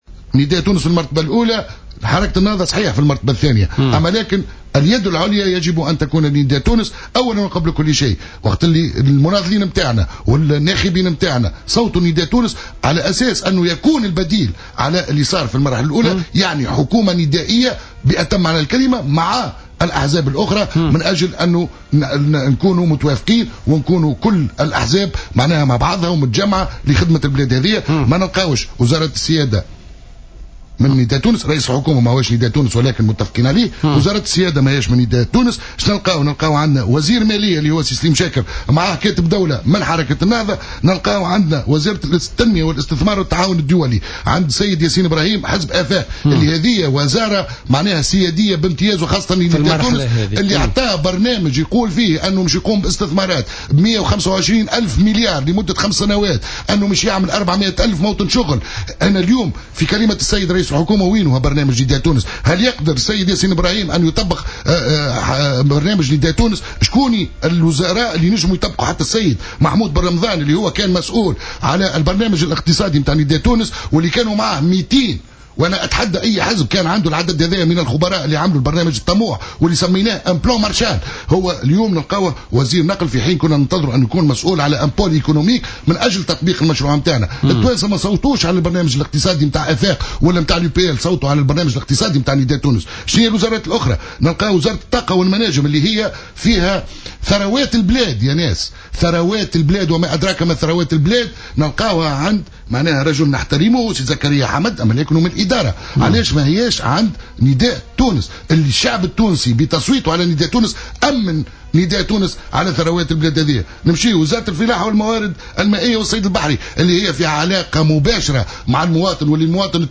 قال النائب عن حركة نداء تونس، عبد العزيز القطي ، ضيف بوليتيكا اليوم الاثنين إن العديد من الوزارات الحساسة في حكومة الحبيب الصيد كانت من المفروض أن تمنح لحركة نداء تونس، لتقوم بالإصلاحات الضرورية في مختلف القطاعات وليتمكن الحزب من تنفيذ برنامجه الانتخابي باعتبار أن الناخبين صوتوا لصالح مشروع نداء تونس الانتخابي.